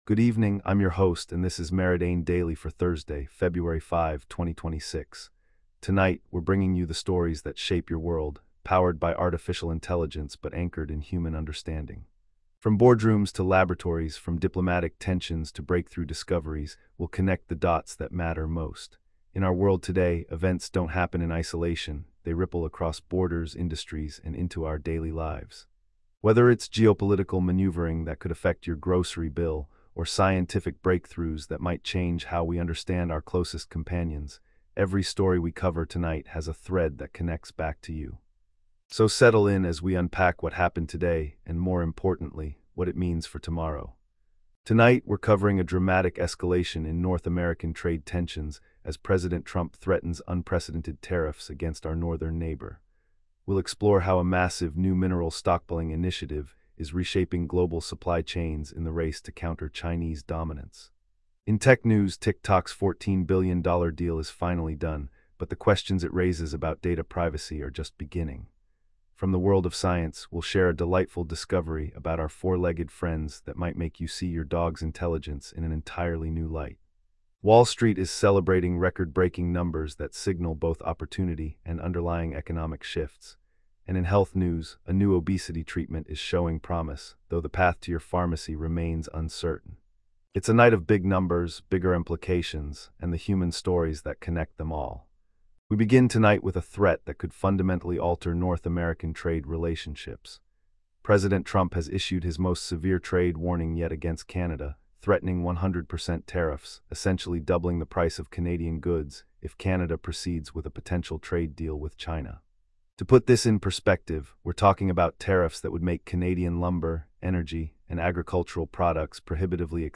Your nightly AI-powered news briefing for Feb 5, 2026